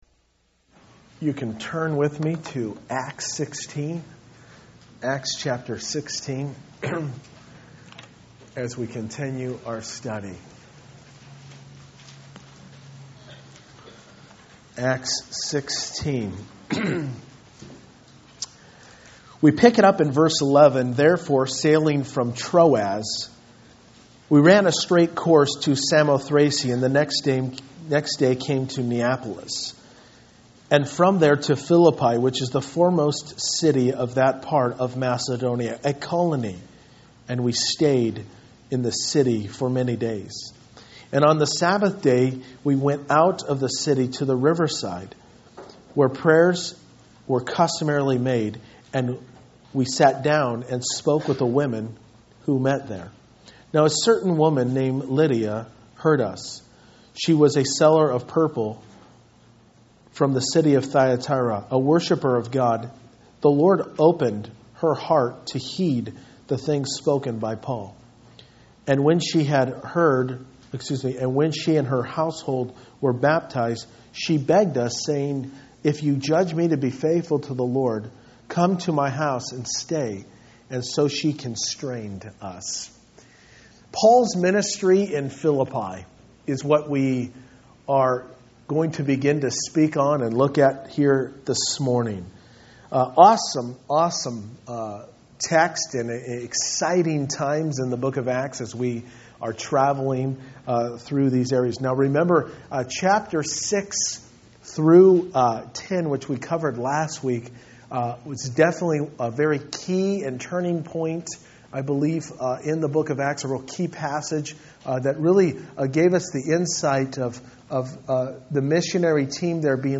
Verse by Verse-In Depth « Sensitivity to the Leading of the Holy Spirit